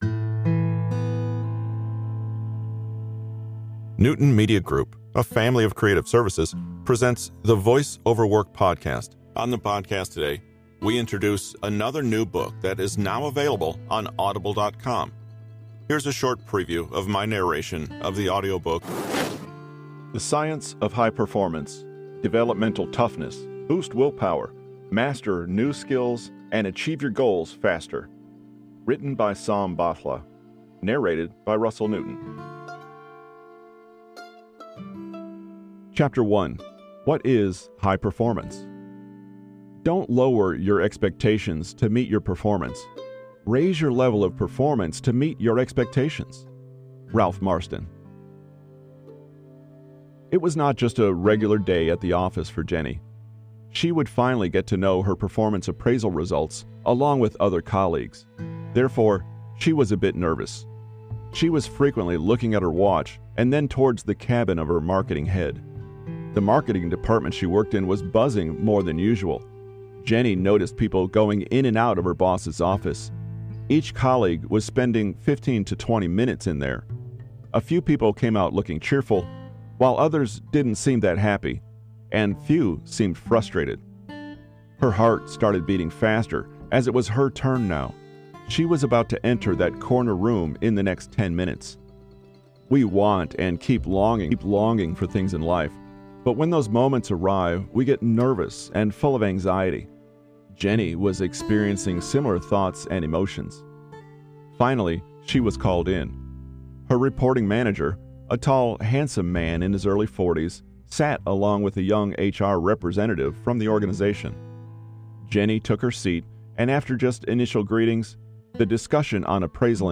Use Crisis For The Opportunity It Is - Voice over Work - An Audiobook Sampler